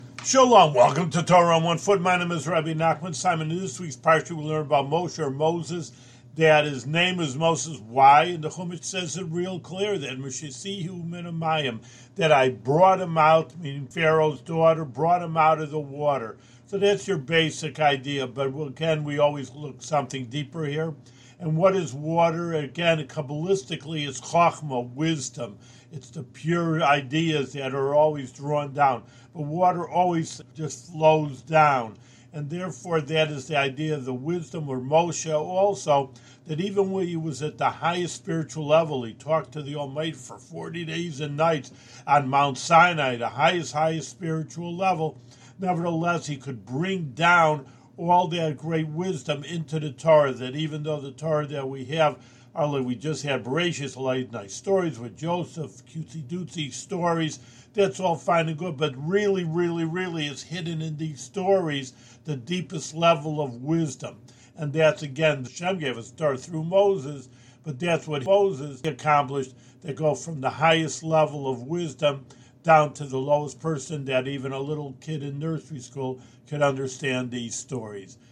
One-minute audio lessons on special points from weekly Torah readings in the Book of Exodus.